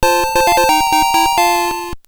item_found.wav